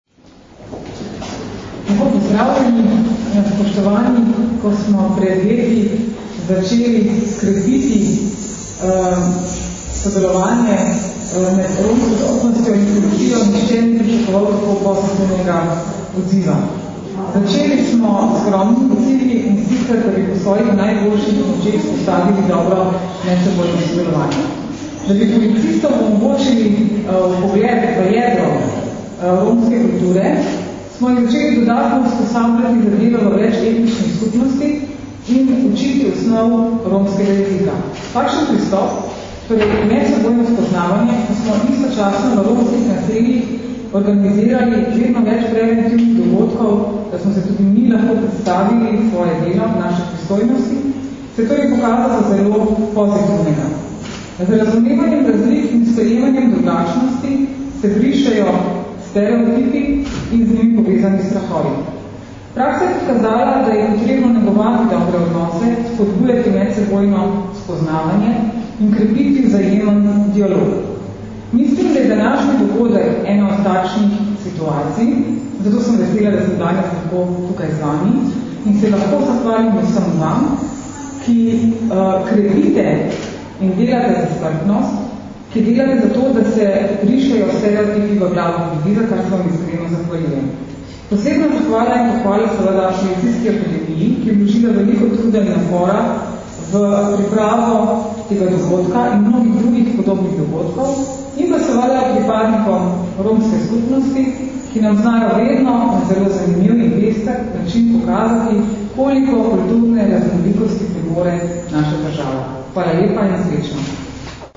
Zvočni posnetek izjave Tatjane Bobnar (mp3)